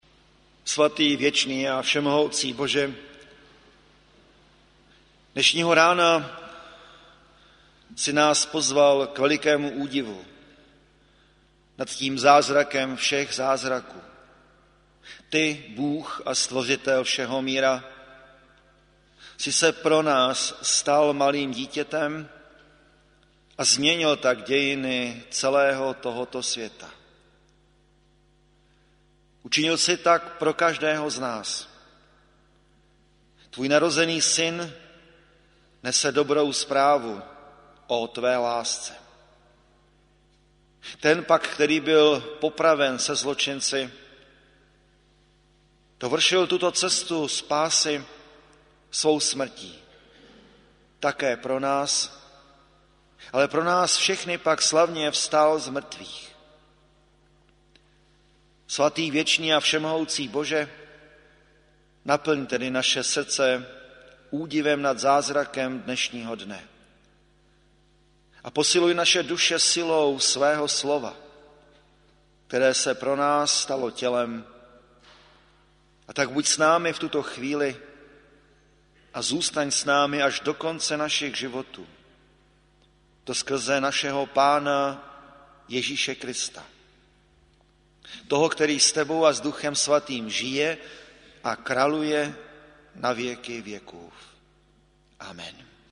bohoslužby se slavením svaté večeře Páně